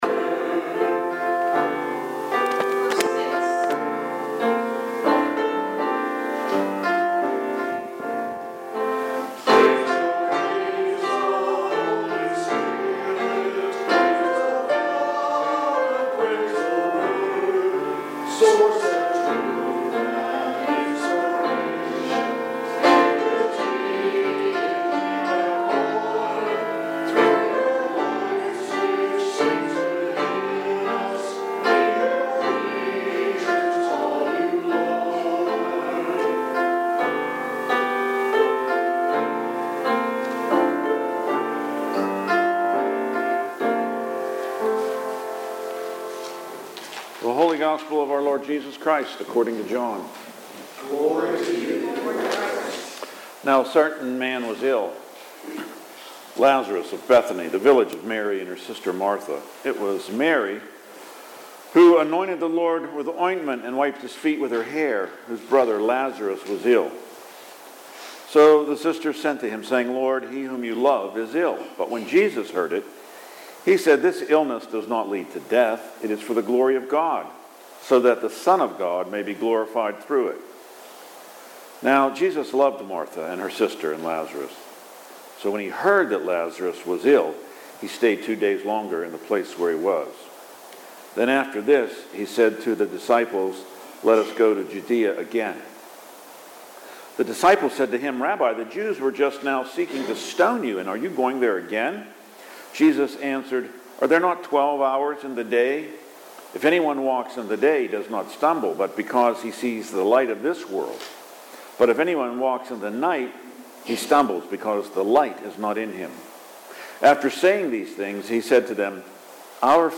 Readings and Sermon March 22 – Saint Alban's Anglican Church